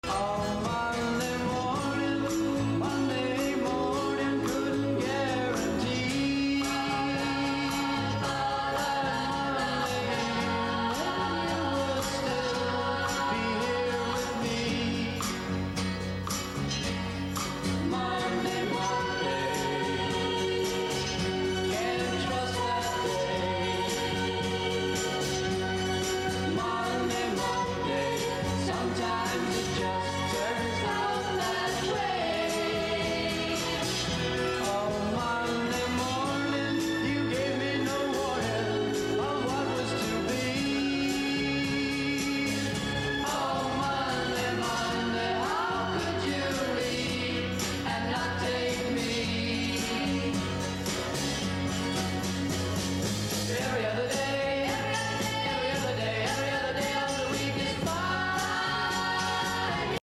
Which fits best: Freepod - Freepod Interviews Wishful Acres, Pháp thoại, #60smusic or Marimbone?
#60smusic